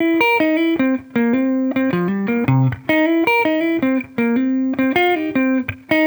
Index of /musicradar/sampled-funk-soul-samples/79bpm/Guitar
SSF_TeleGuitarProc1_79C.wav